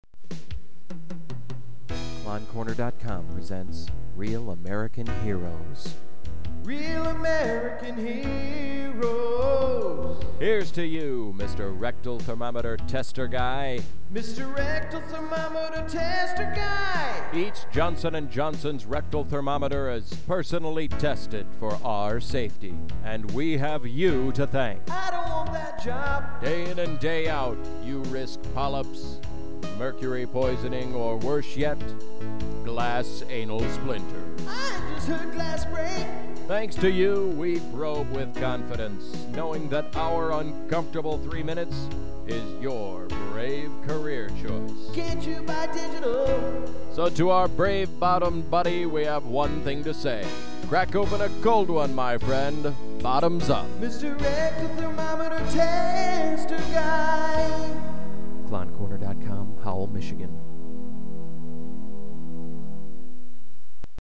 I recorded a spoof of the Budweiser radio commercials, music and all, and I was asked to post it here. Volume's a bit low but